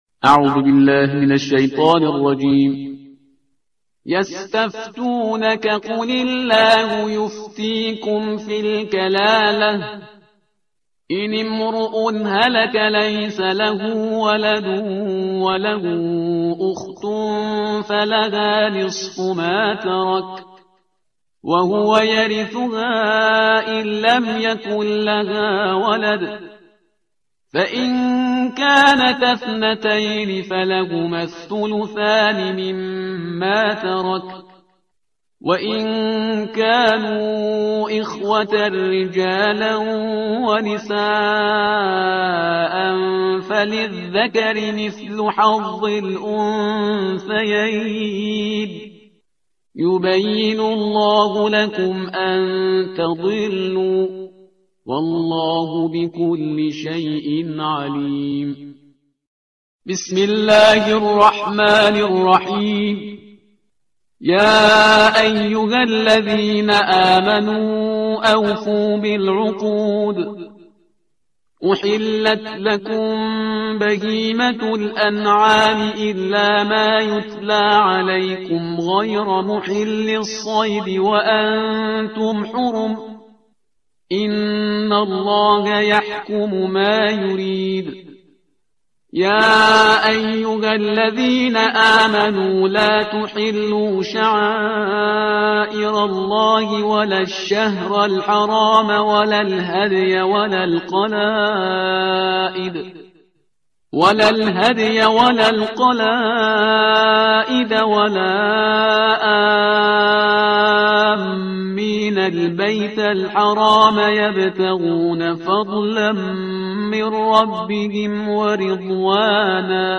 ترتیل صفحه 106 قرآن – جزء ششم